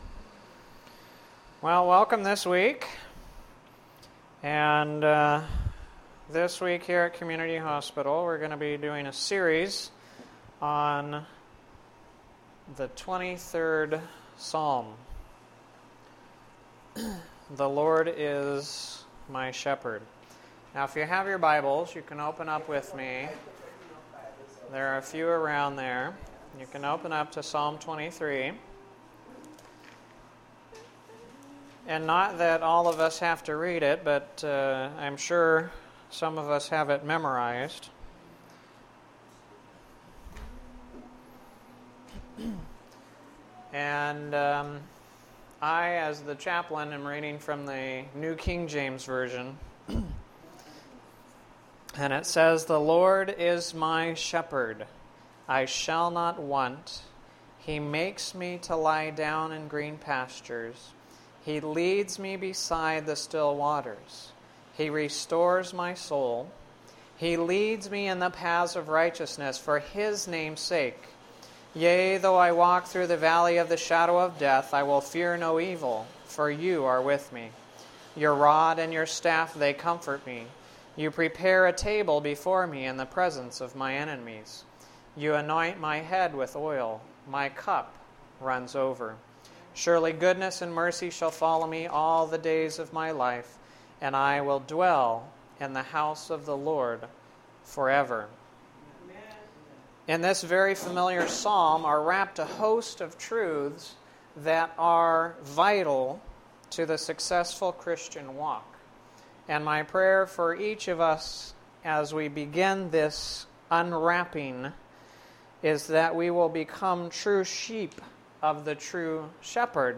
This is a series done for worships at the Community Hospital during the week of January 10-14, 2011. It is based upon Psalm 23.